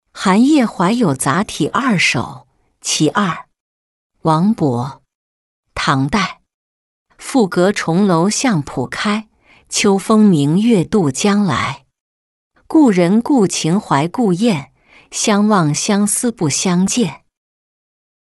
清明夜-音频朗读